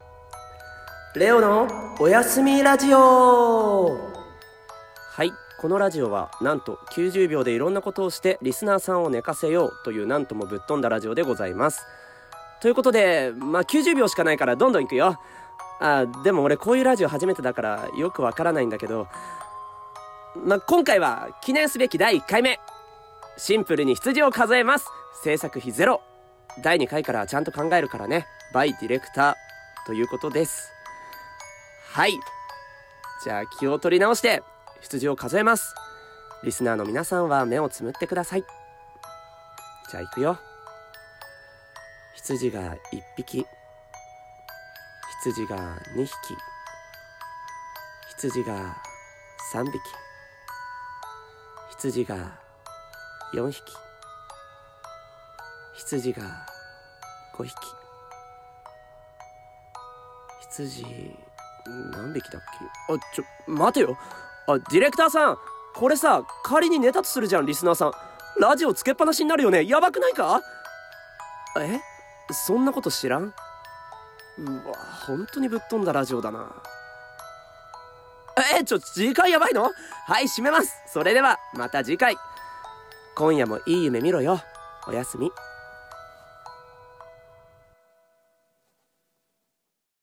【ラジオ風声劇】おやすみラジオ